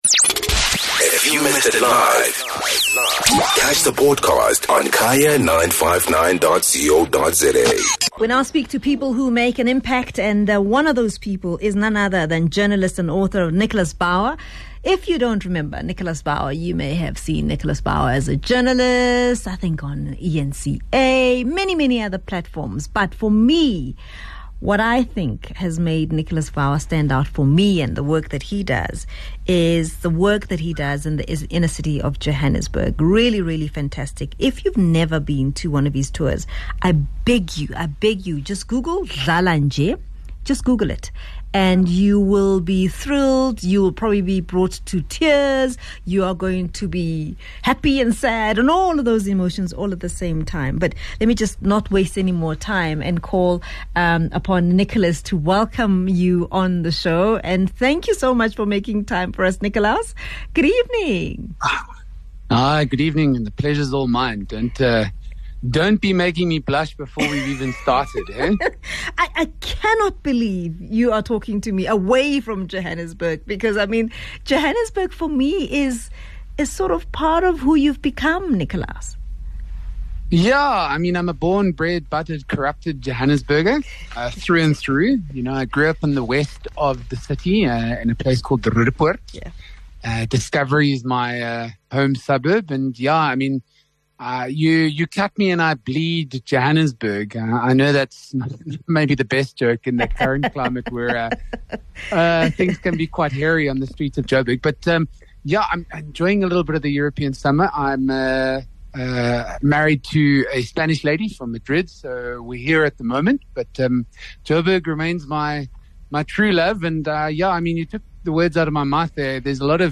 16 Sep Profile Feature